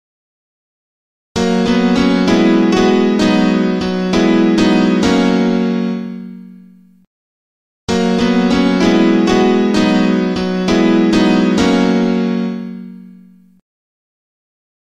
How to Play Piano Lick